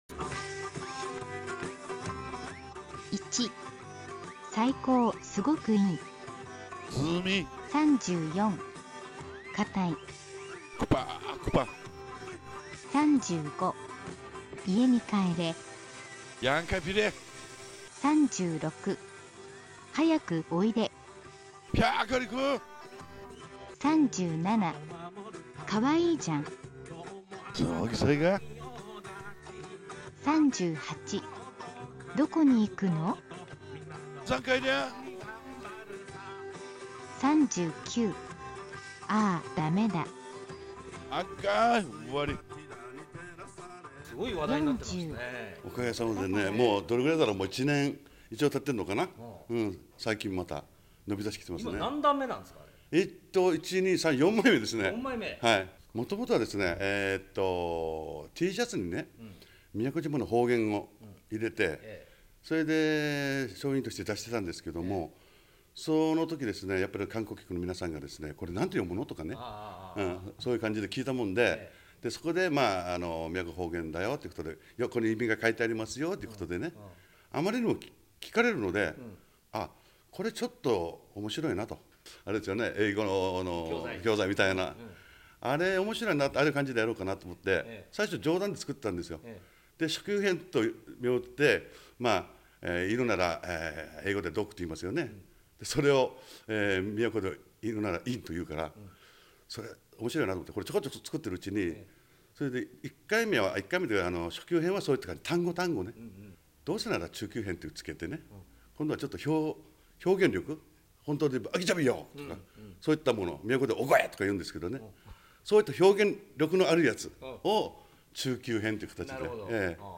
宮古方言